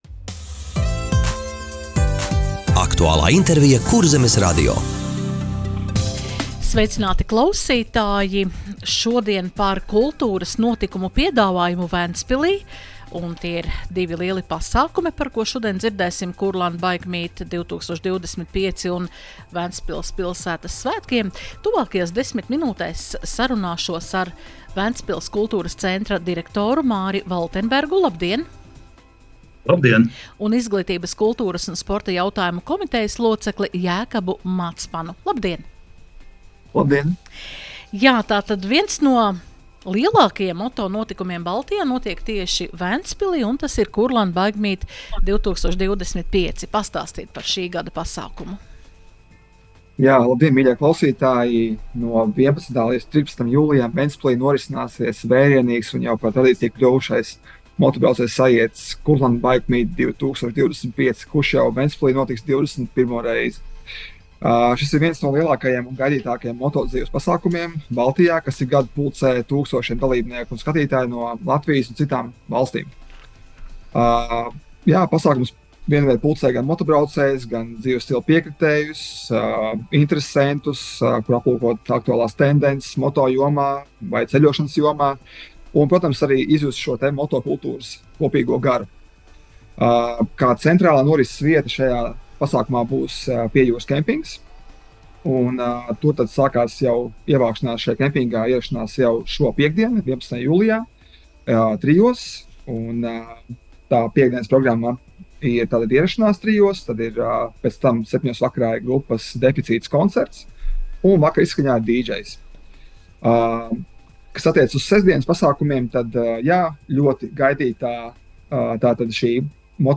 Radio saruna Ventspils Kultūras centra aktualitātes - Ventspils